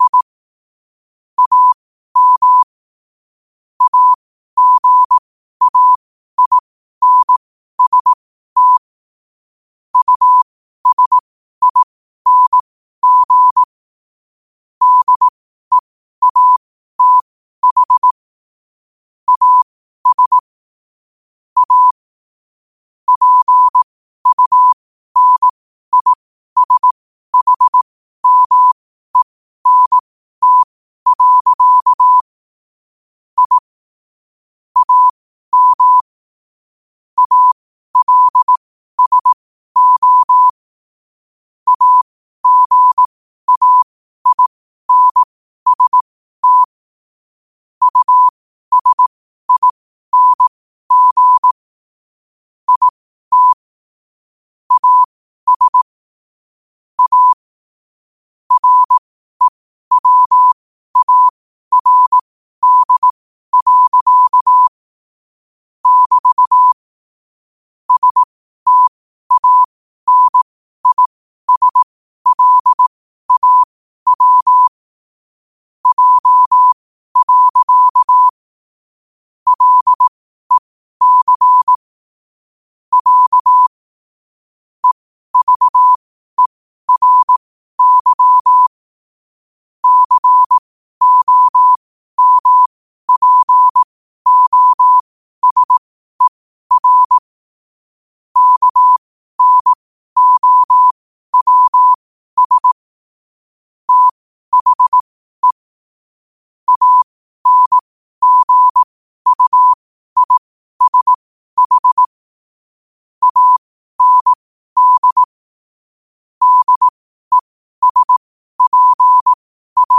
New quotes every day in morse code at 12 Words per minute.